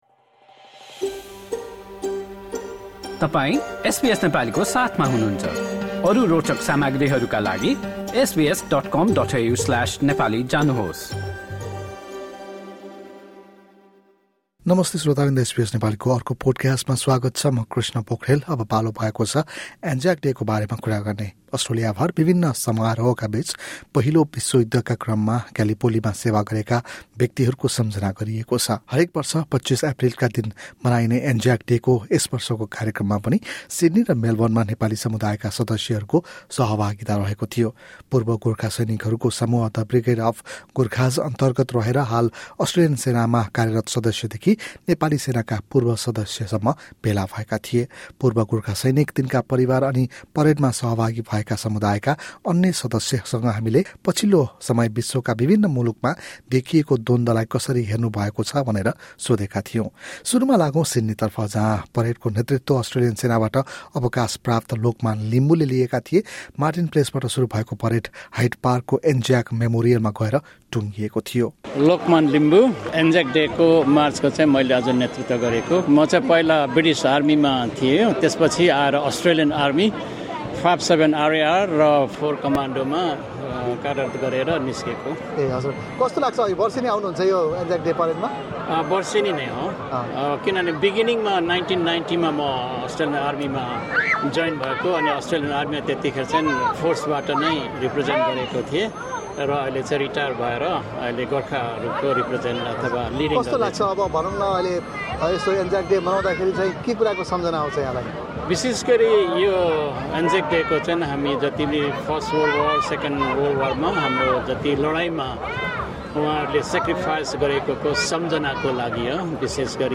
This year’s commemorations in Sydney and Melbourne again saw participation from the Nepali-speaking community led by the Brigade of Gurkhas, including serving members of the Australian Defence Force (ADF) and former members of the Nepali Army (NA). SBS Nepali spoke to participants, including veterans, their families, and other community members and leaders who took part in the parades, about the present-day conflicts around the world and the ongoing impact of war.